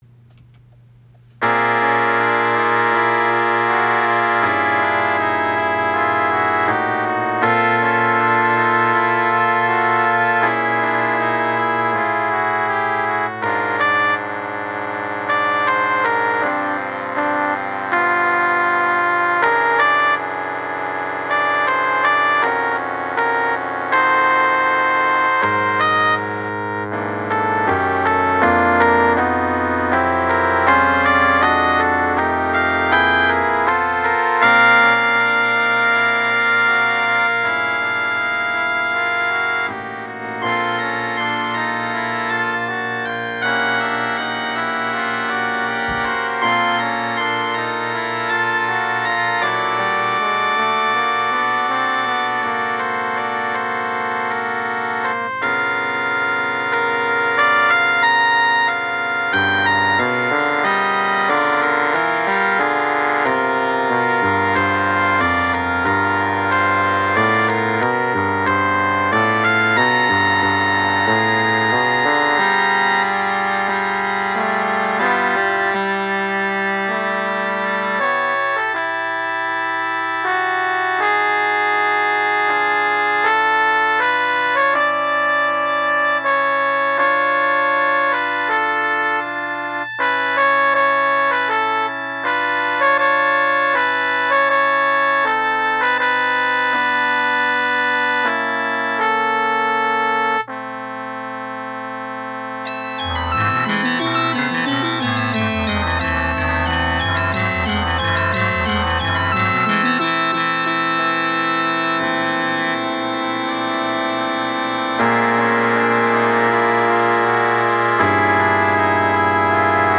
A Day at the Friary, for concert band, written for the Friary Guildford Band.